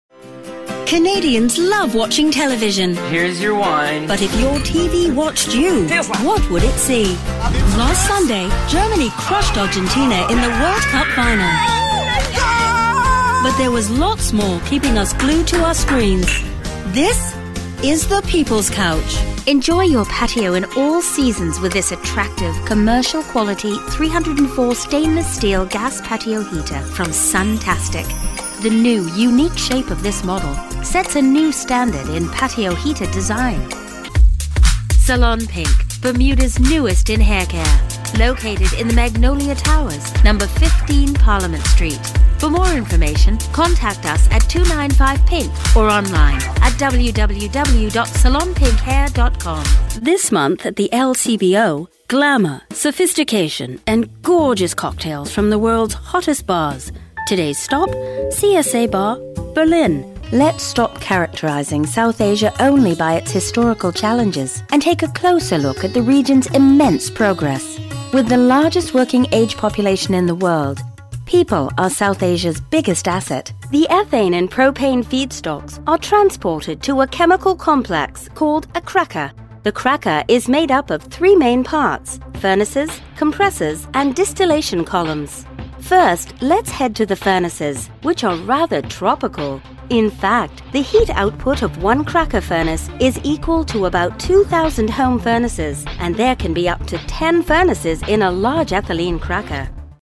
Female
Bright, Confident, Corporate, Friendly, Natural, Posh, Reassuring, Smooth, Soft, Warm, Versatile, Young, Approachable, Authoritative, Conversational, Energetic, Engaging, Upbeat
Canadian (native) neutral North American British RP
Audio equipment: professionally built booth / UR22mkII interface